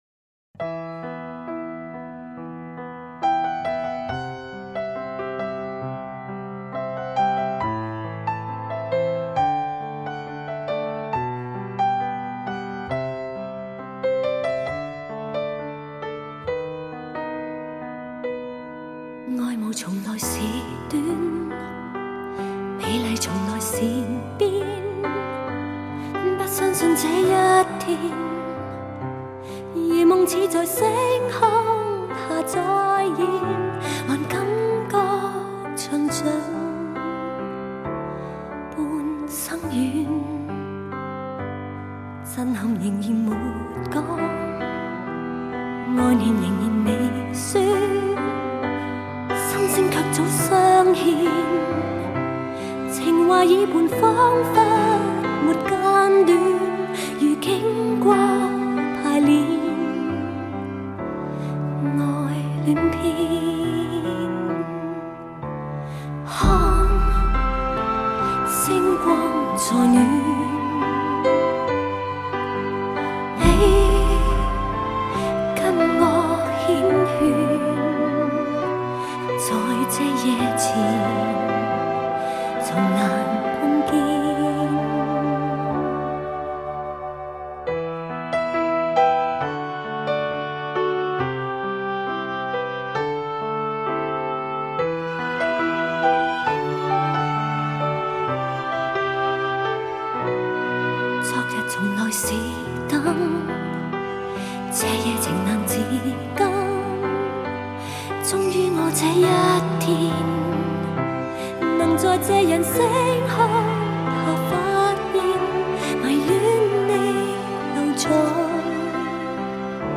音场透明度及层次感大大增强。
频率明显扩张，动态更具震撼。